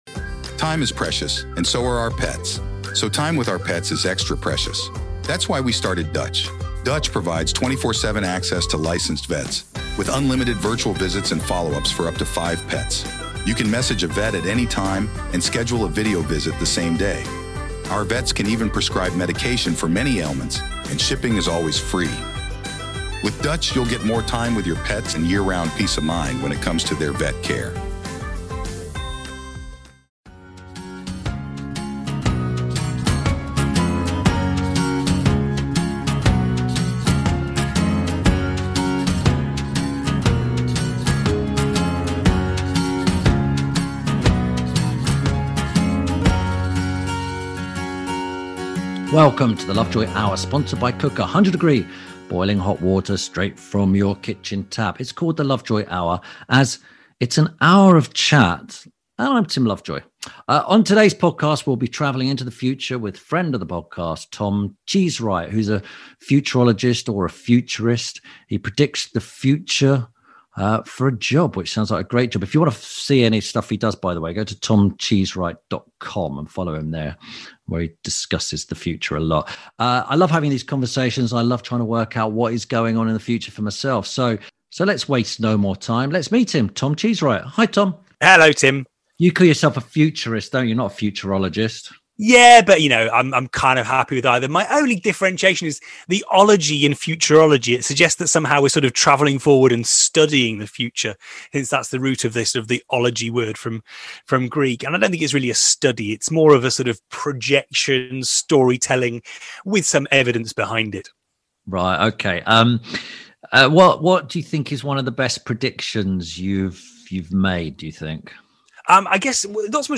A discussion on what will happen in the future